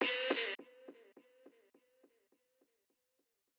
Vox
yeahehe.wav